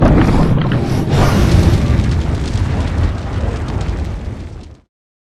firebreath1.wav